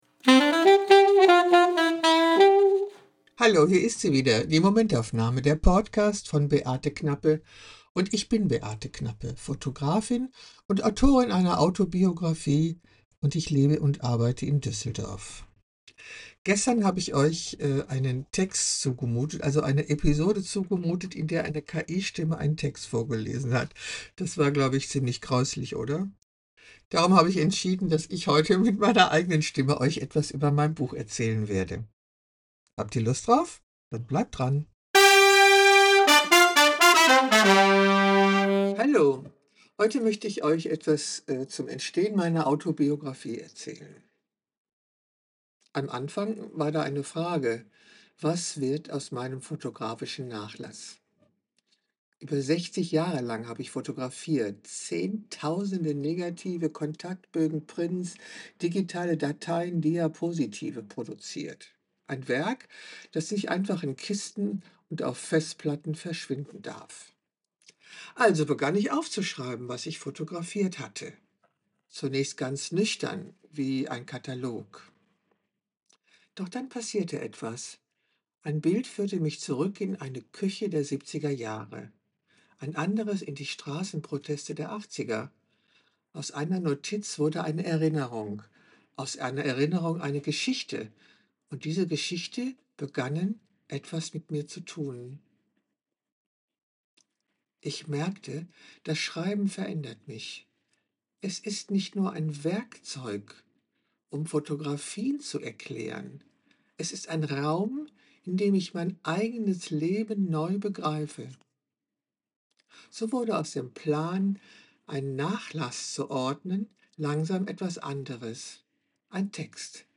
liest
meiner eigenen Stimme.
Meine Stimme trägt den Rhythmus meiner Sprache, die Atempausen